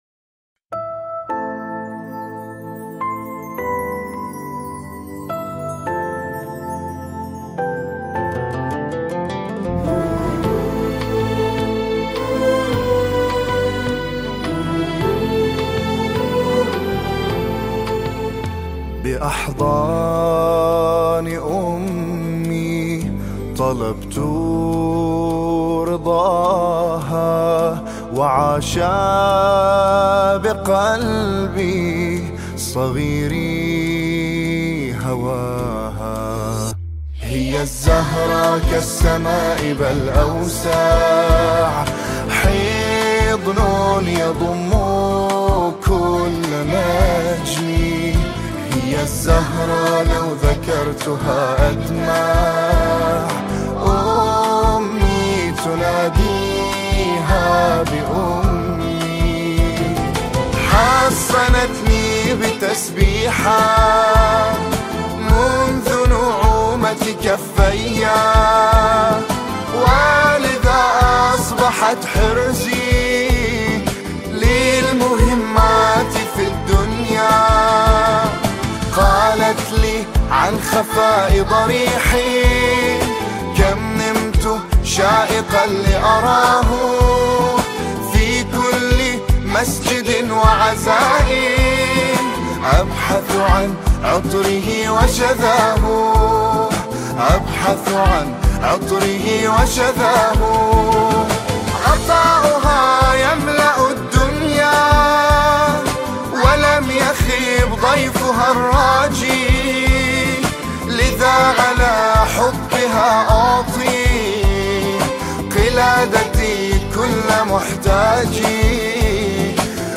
نماهنگ عربی زیبای
ویژه جشن ولادت حضرت فاطمه سلام الله علیها